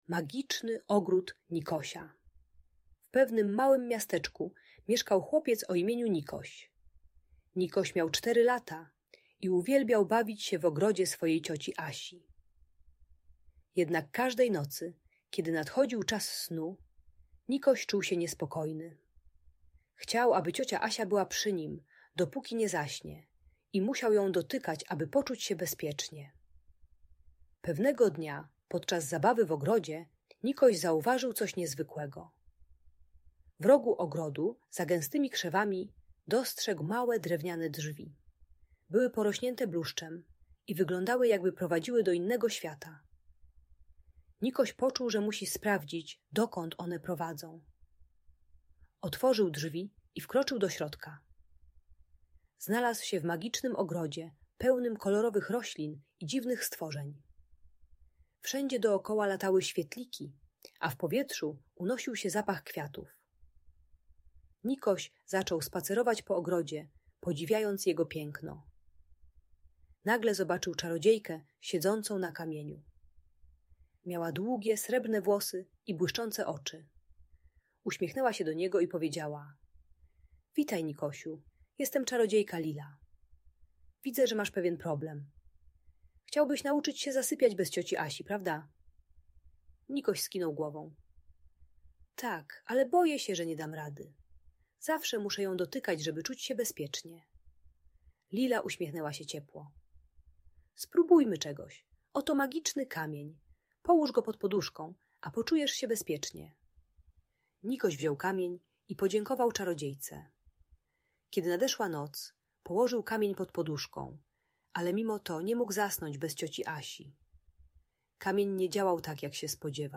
Magiczny Ogród Nikosia - Historia o Odwadze i Przyjaźni - Audiobajka